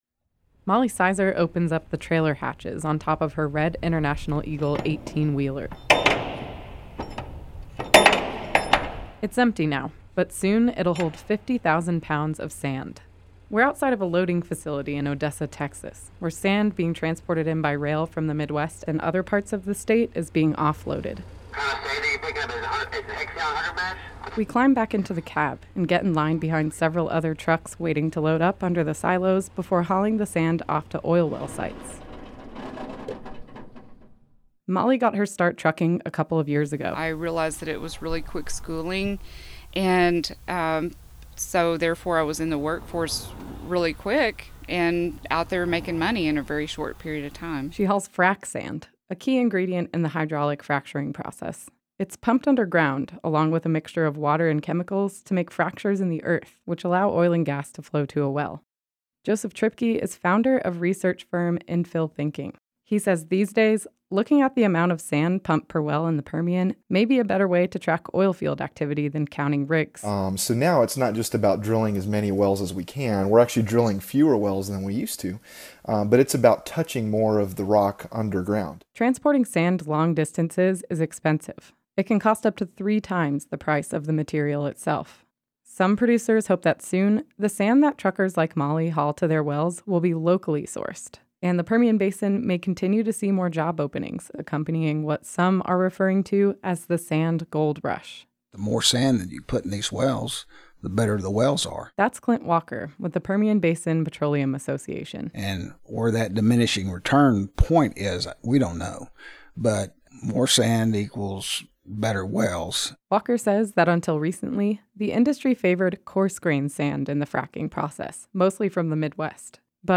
In The News: Marfa Public Radio Interview